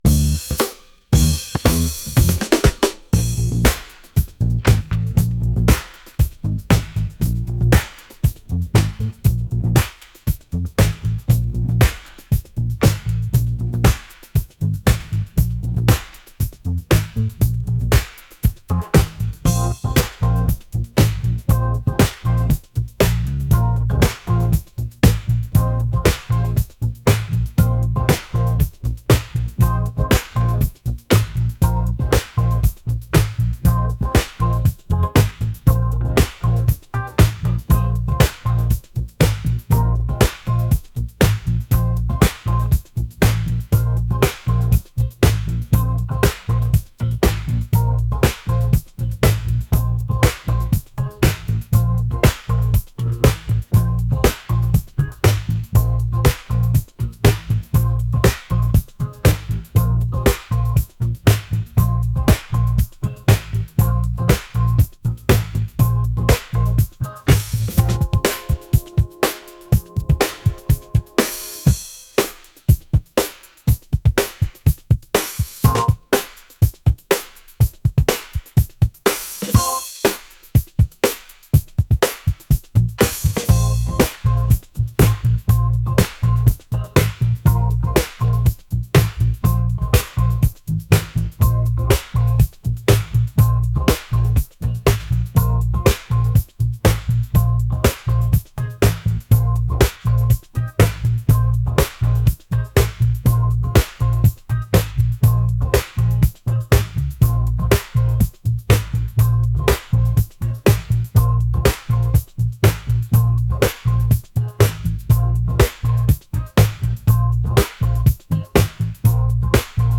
soul | funky